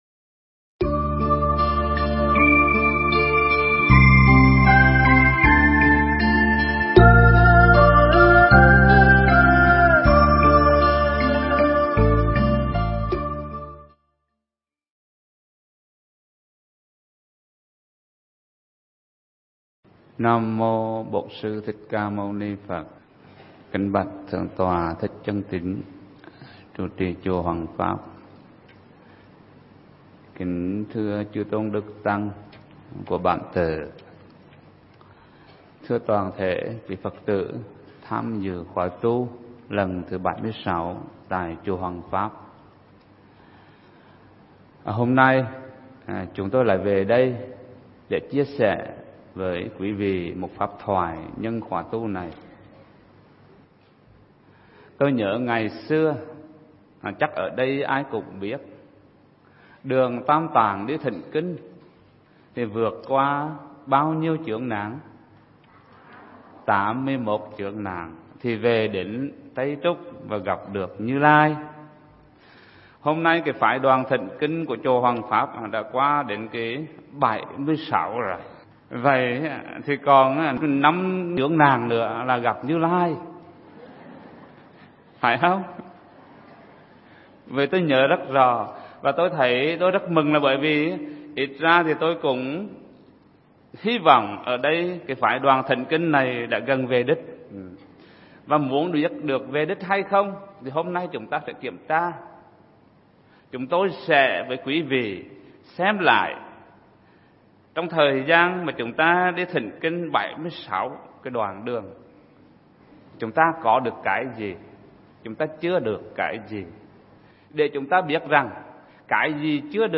Pháp âm Cách Hành Xử Của Người Giải Thoát
giảng trong khoá tu tại chùa Hoằng Pháp